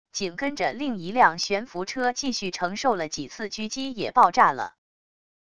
紧跟着另一辆悬浮车继续承受了几次狙击也爆炸了wav音频生成系统WAV Audio Player